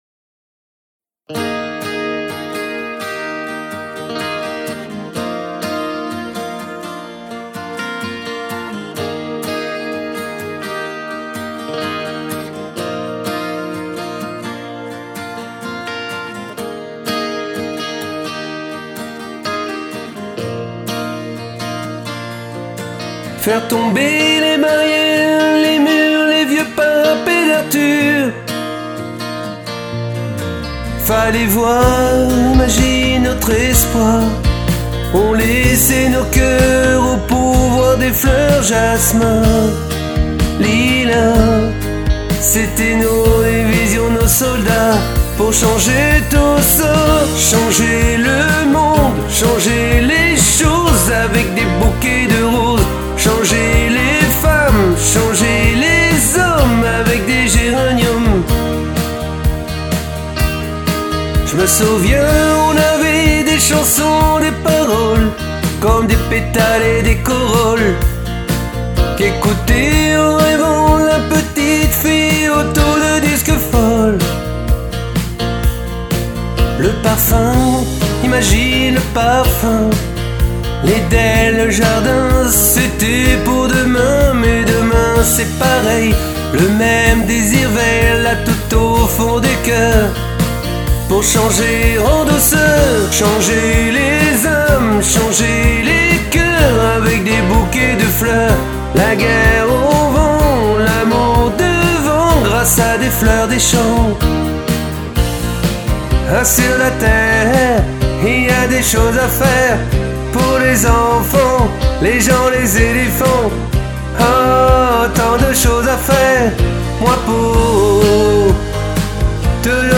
Ténor